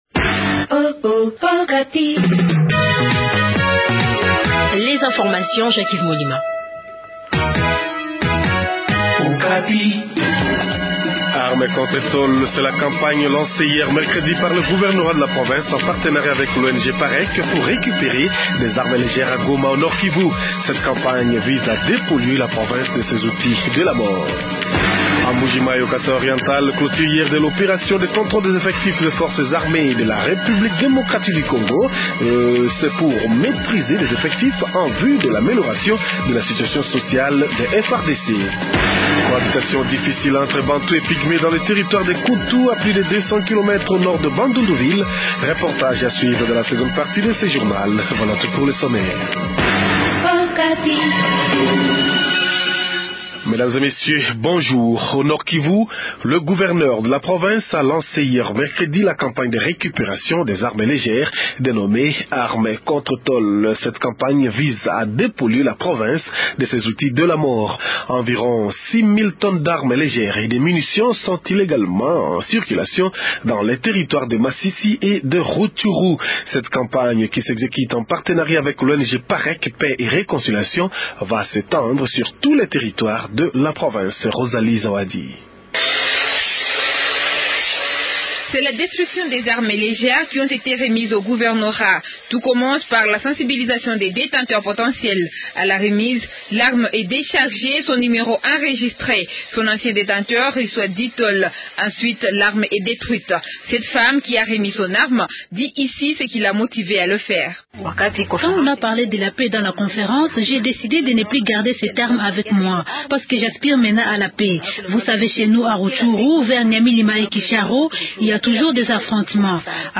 Journal Français Matin